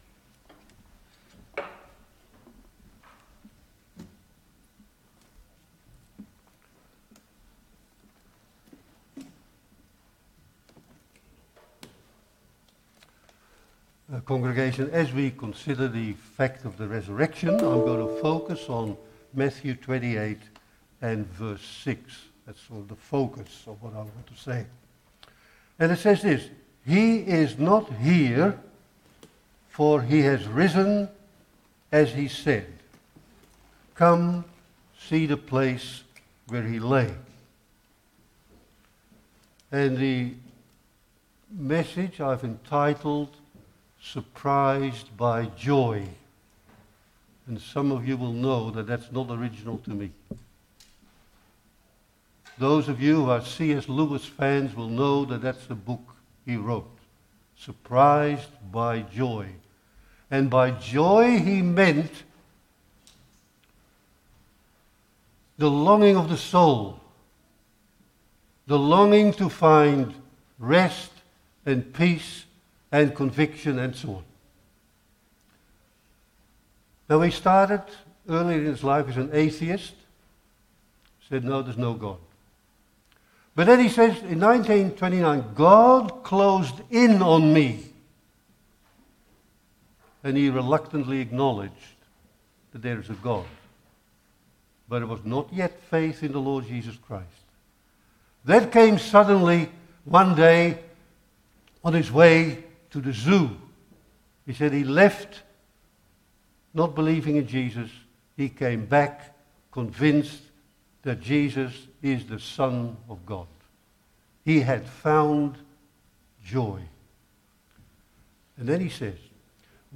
EASTER LITURGY
Geelong Christian Reformed Church